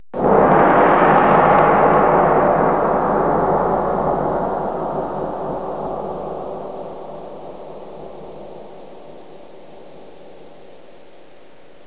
gong.mp3